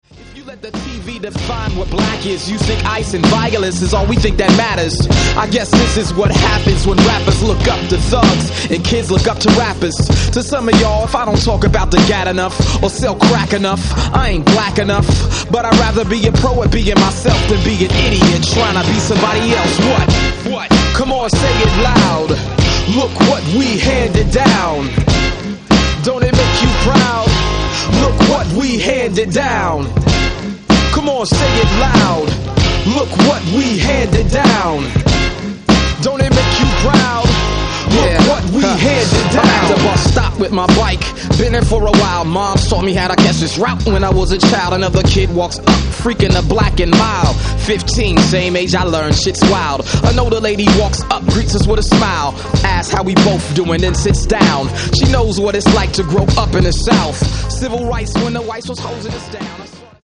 indie hip hop